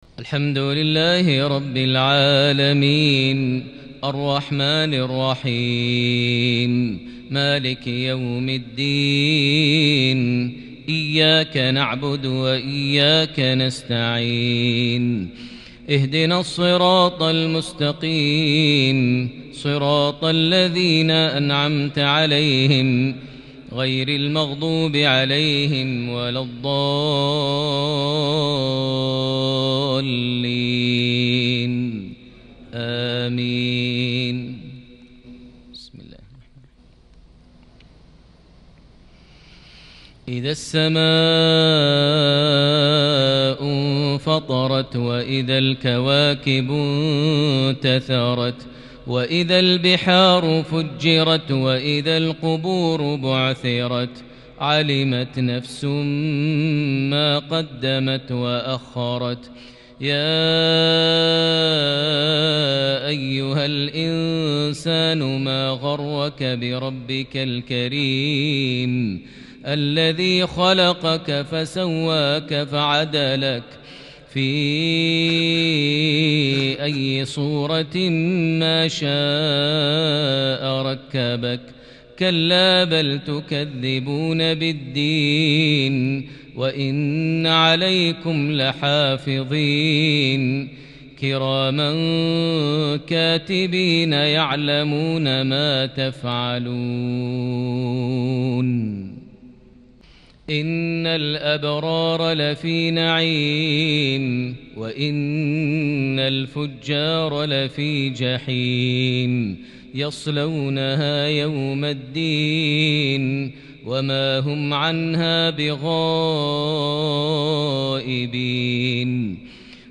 صلاة العشاء ٣ شوال ١٤٤١ سورة الانفطار > 1441 هـ > الفروض - تلاوات ماهر المعيقلي